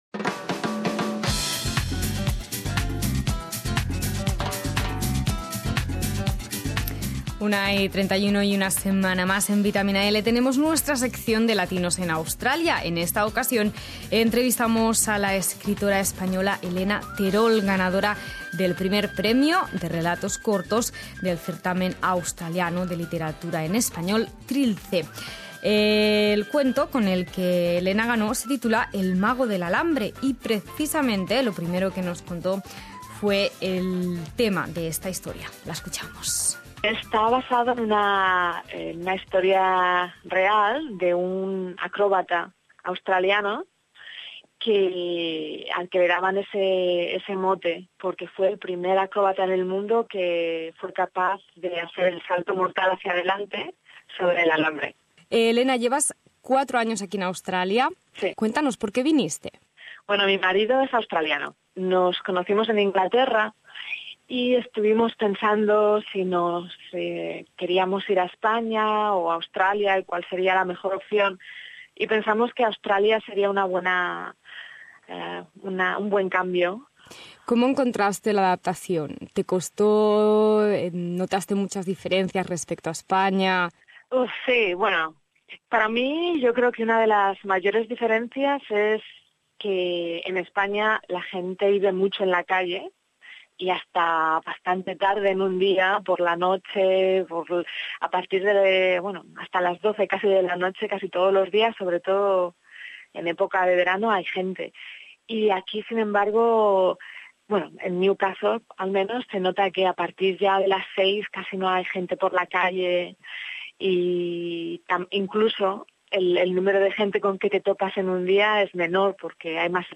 Latinos en Australia: Entrevista